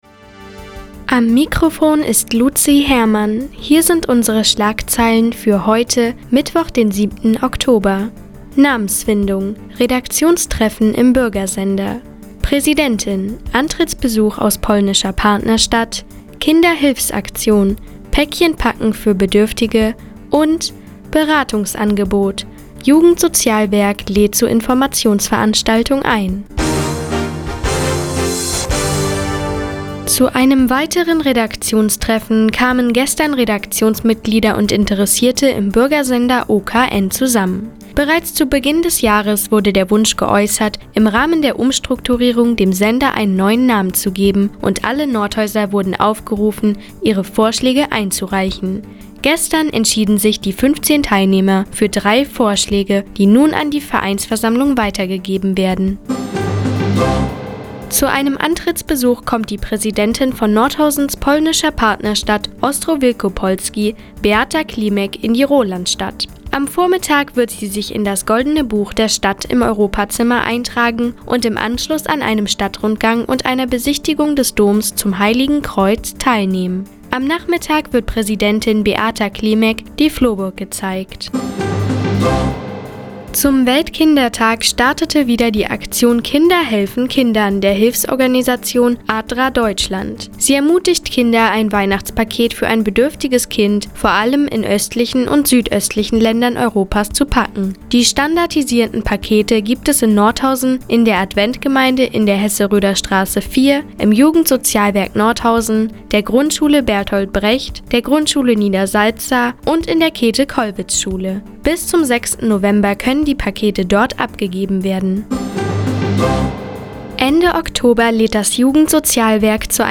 Mi, 17:00 Uhr 07.10.2015 Neues vom Offenen Kanal Nordhausen „Der Tag auf die Ohren“ Seit Jahren kooperieren die Nordthüringer Online-Zeitungen, und der Offene Kanal Nordhausen. Die tägliche Nachrichtensendung des OKN ist jetzt hier zu hören.